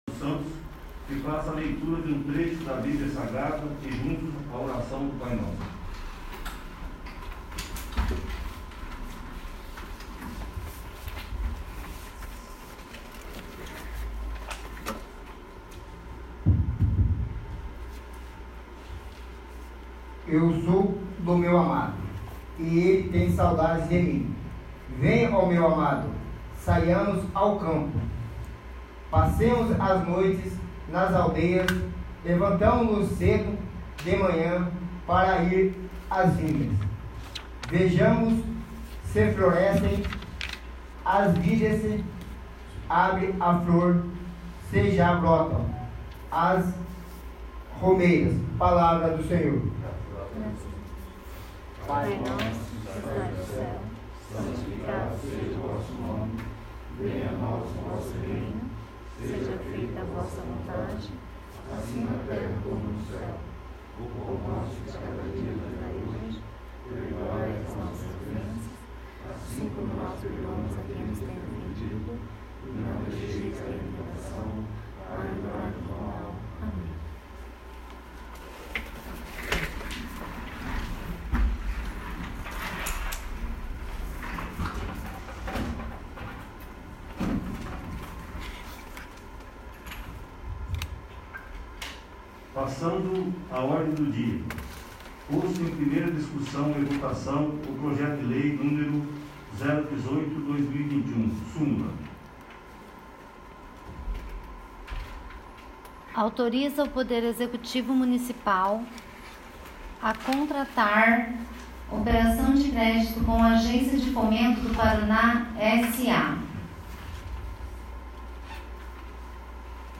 4º. Sessão Extraordinária